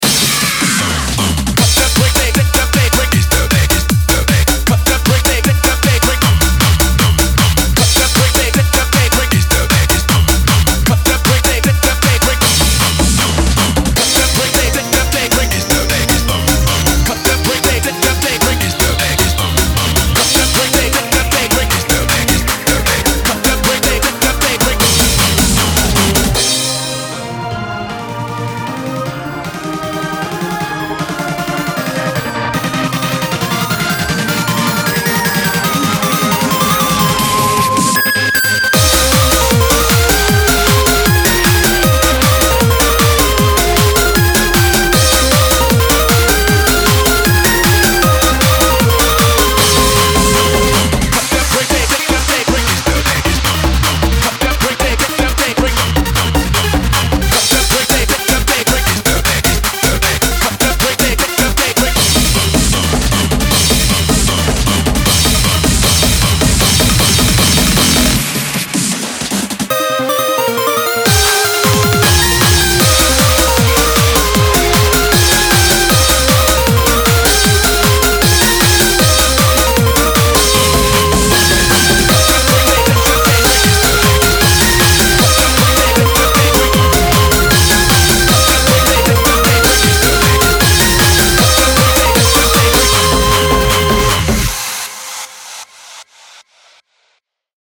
BPM155
Audio QualityPerfect (High Quality)
unique vocal samples